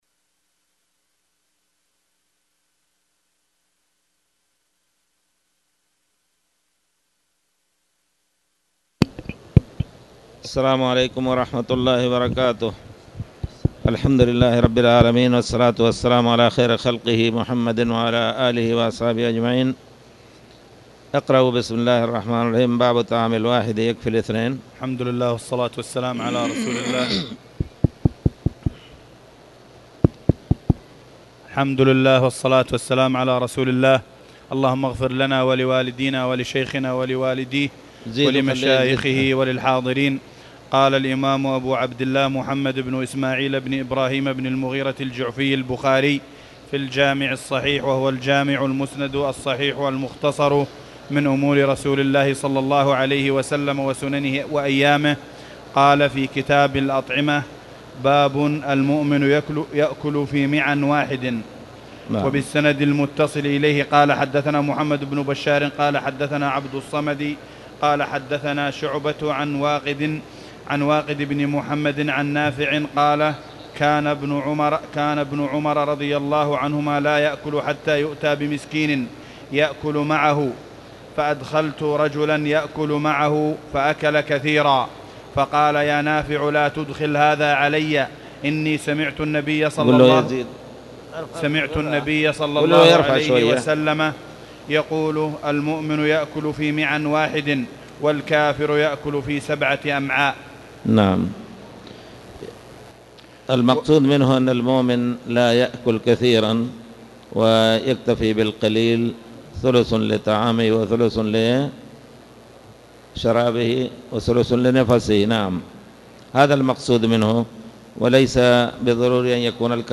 تاريخ النشر ١٨ ربيع الأول ١٤٣٨ هـ المكان: المسجد الحرام الشيخ